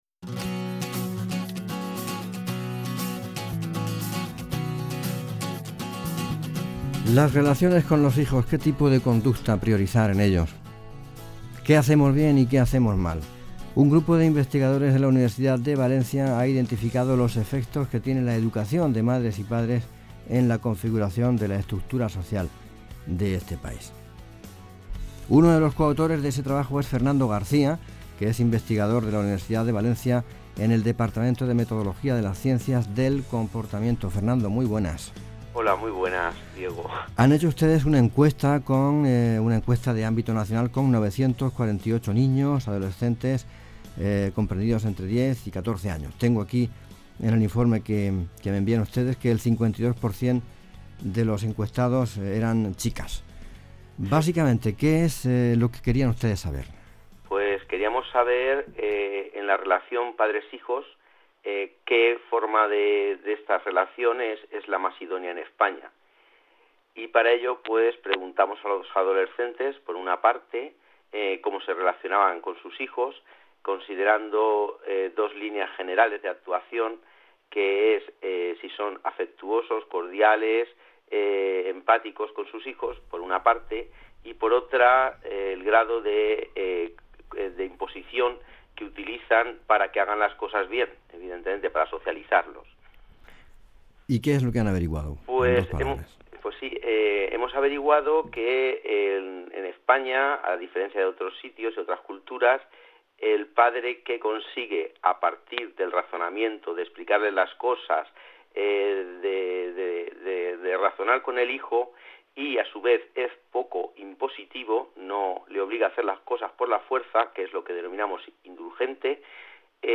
08/11/2010 (Radio Interview) Radiotelevisión de la Región de Murcia